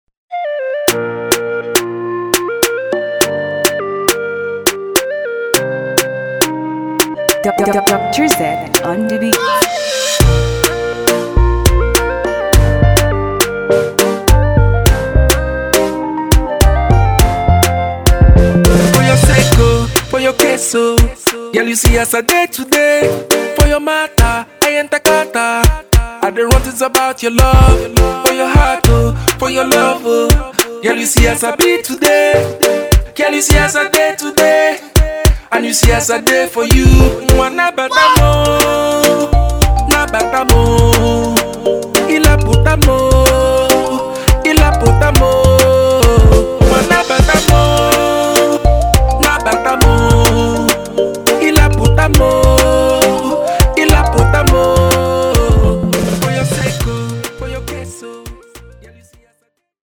soothing and melodious
including Afro-pop/Rock/Soul and even RnB
Afro-pop